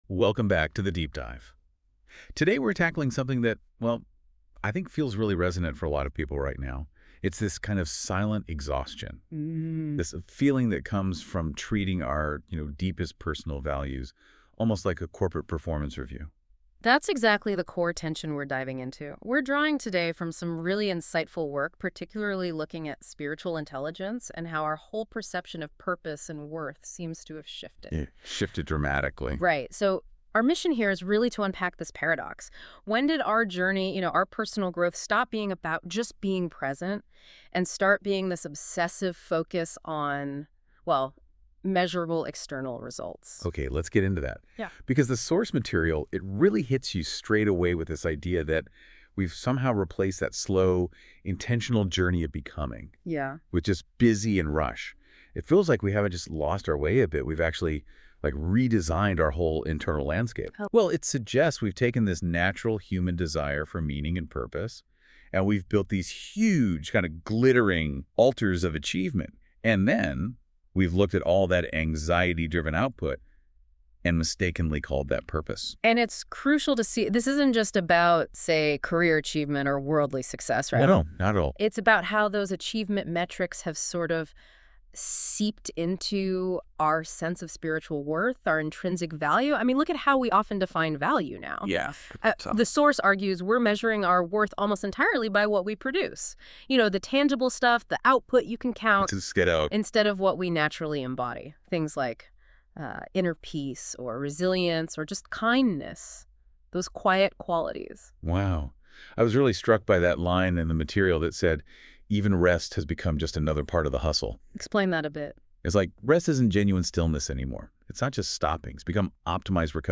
A Conversation about Spiritual Capitalism and how it leads to exhaustion and burnout.